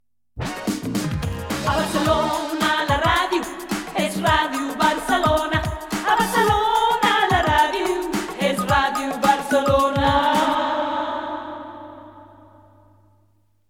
Indicatiu cantat de l'emissora.